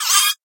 Minecraft Version Minecraft Version latest Latest Release | Latest Snapshot latest / assets / minecraft / sounds / mob / guardian / land_idle3.ogg Compare With Compare With Latest Release | Latest Snapshot
land_idle3.ogg